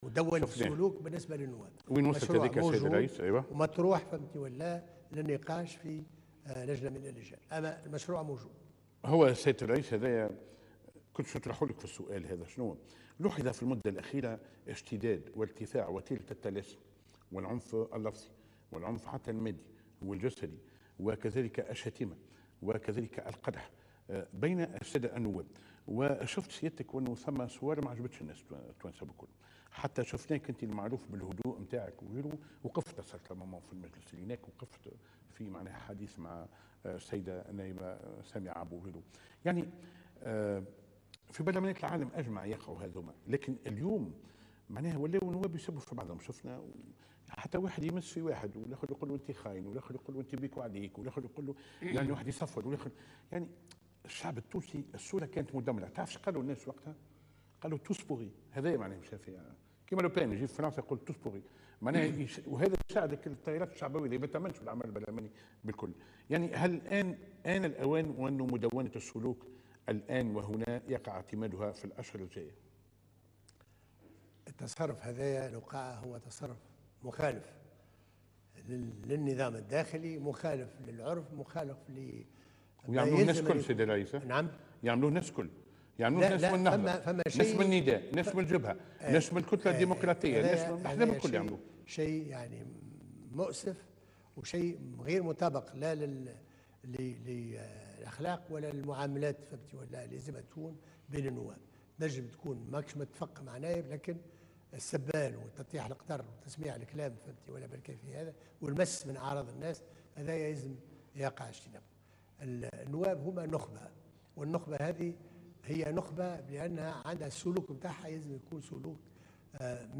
وأضاف في حوار له على القناة الوطنية الأولى أنه سيقع النظر قريبا في مدونة سلوك تضبط تصرفات النواب داخل المجلس.